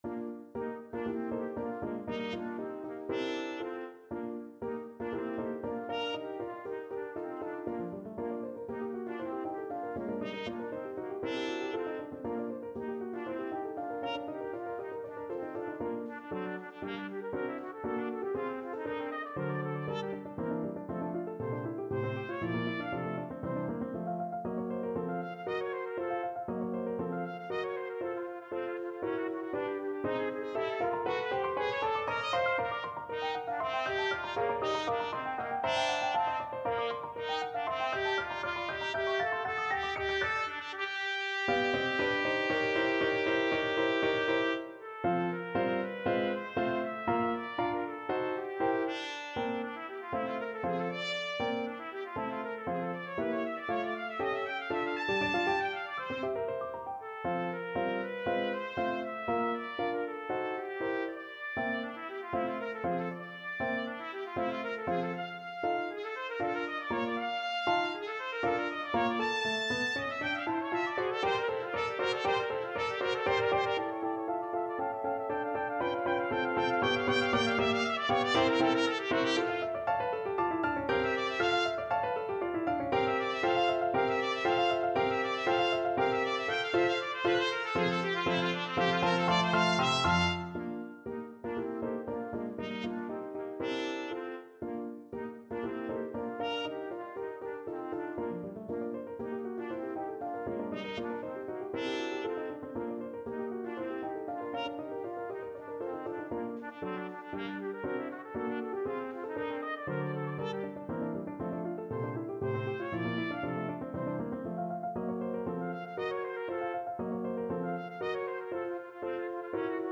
Classical Tchaikovsky, Pyotr Ilyich Nutcracker Suite, Op. 71a I.Overture Trumpet version
Trumpet
Bb major (Sounding Pitch) C major (Trumpet in Bb) (View more Bb major Music for Trumpet )
2/4 (View more 2/4 Music)
Allegro giusto = 118 (View more music marked Allegro)
G4-Bb6
Classical (View more Classical Trumpet Music)